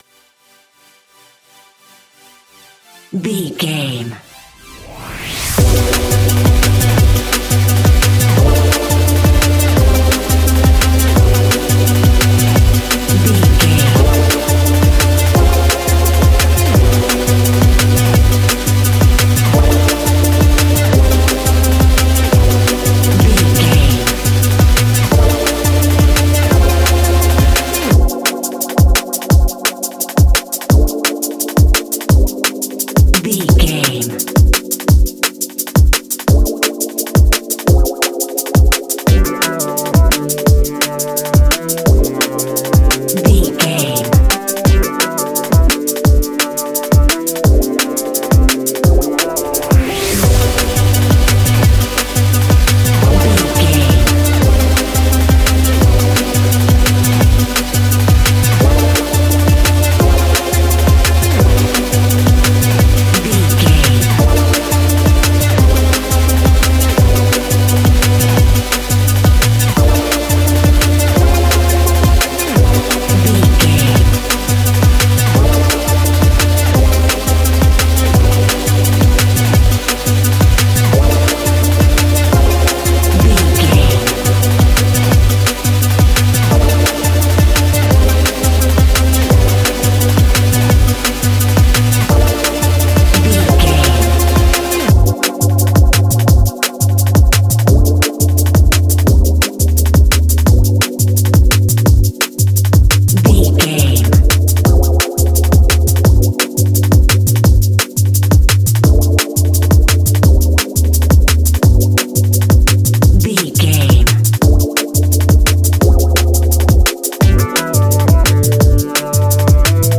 Ionian/Major
electronic
techno
trance
synths
synthwave
instrumentals